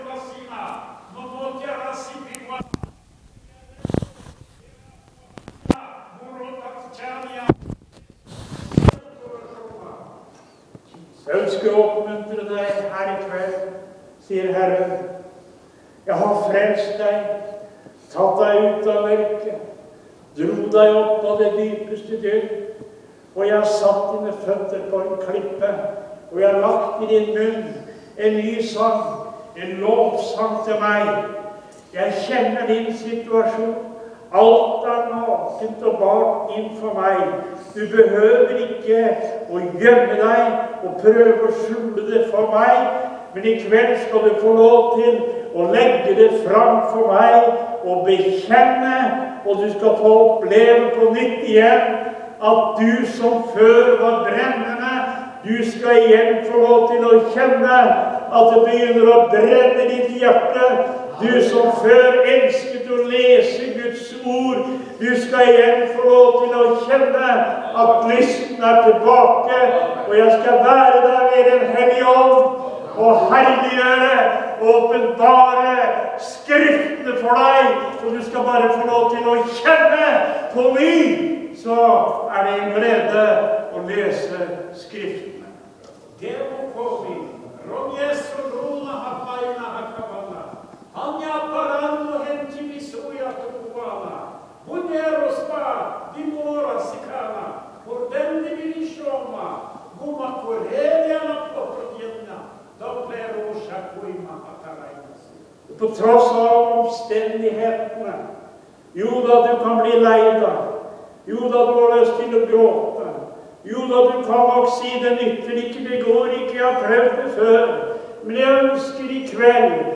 Møte i Maranata 10.3.2013.
Tale